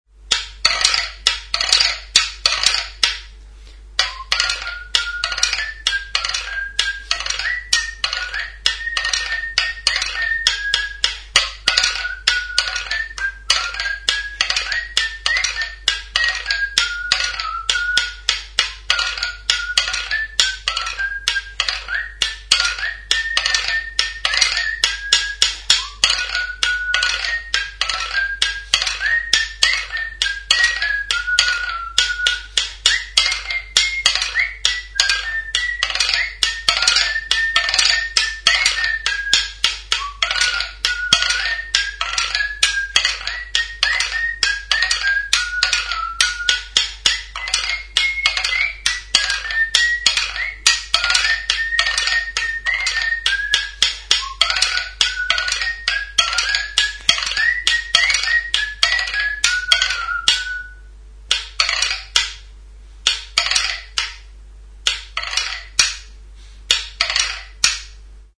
Idiophones -> Scraped
Zurezko eskaileratxo gisako tresna da.
Arraskatzeko beste makilatxo bat dauka, sokatxo batekin loturik.